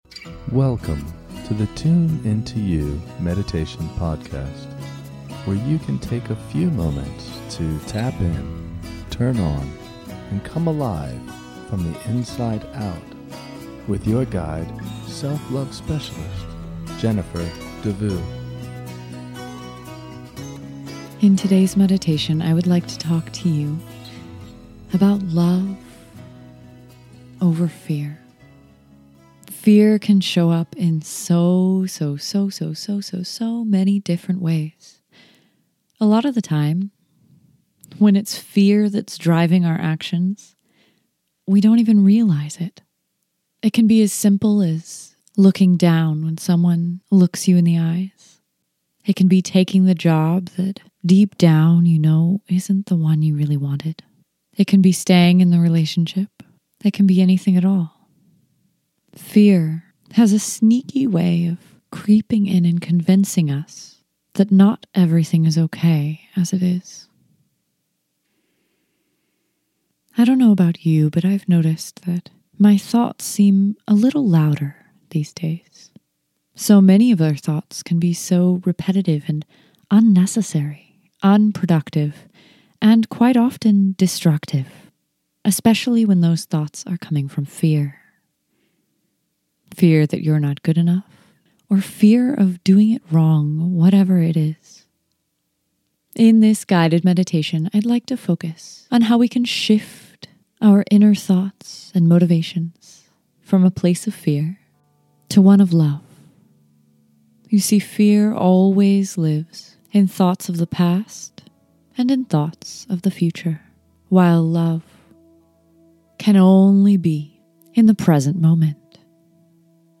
In this short guided meditation, we will identify a few truths about fear and how we can begin to choose to overcome it over and over. In this meditation, I invite you deeply into the present moment where you have the choice in the quality and content of your own inner space.